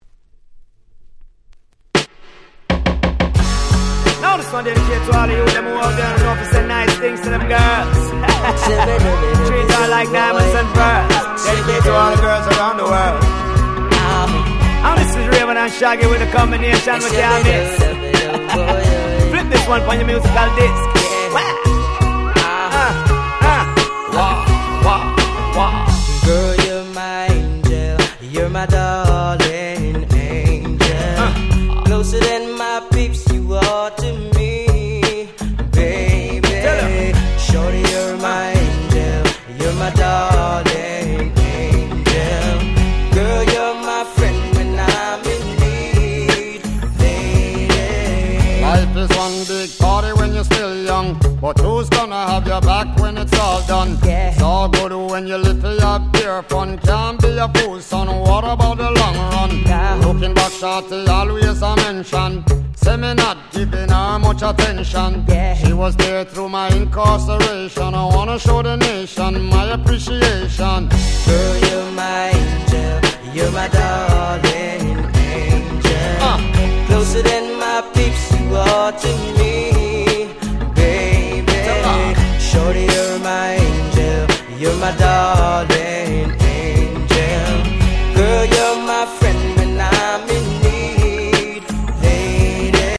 01' Super Hit Reggae !!